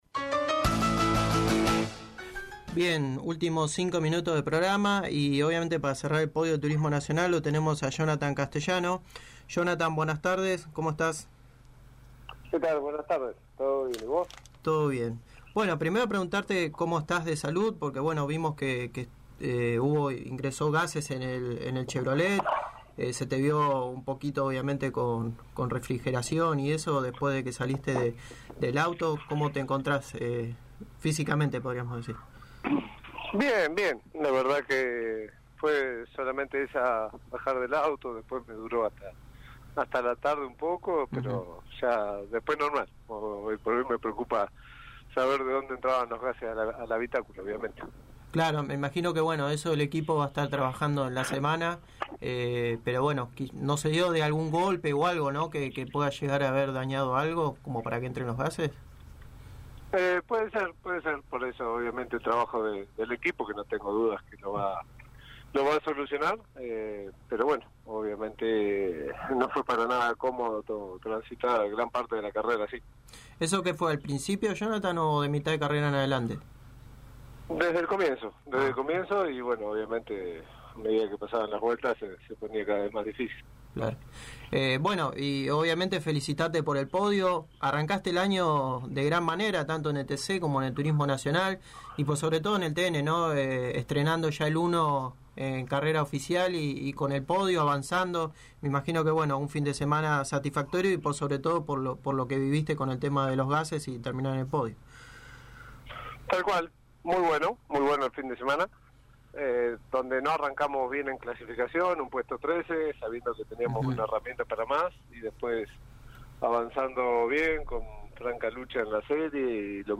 El campeón de la categoría pasó por los micrófonos de Pole Position y habló sobre el podio obtenido en Alta Gracia, en la fecha apertura del Turismo Nacional clase 3. Además, habló de la inalacion de gases que tuvo durante el transcurrir de la competencia, que una vez terminada la competencia tuvo que ser asistido, sin consecuencias de gravedad.